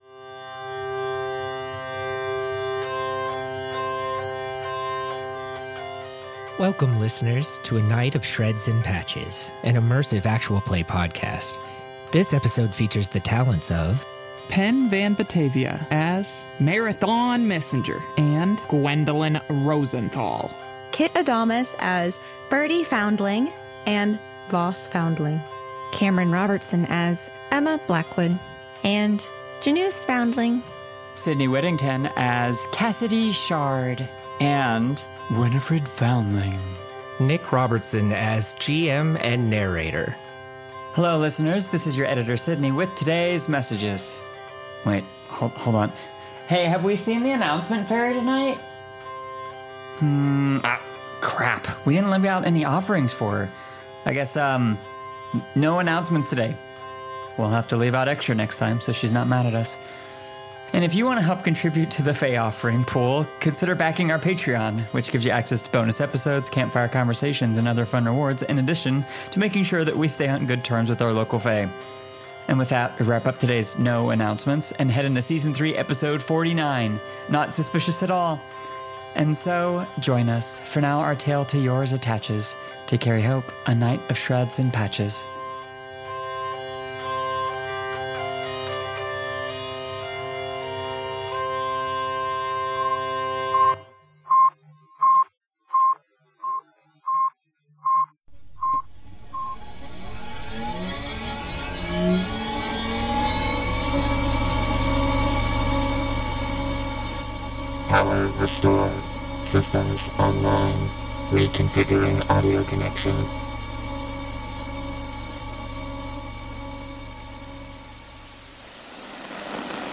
An immersive Actual Play Podcast following the adventures of the Patina, a small mercenary company trying to make ends meet in a world set long after the society of today falls. Environments are green and communities and cities come together to support each other, leveraging what wild tech is left to be salvaged.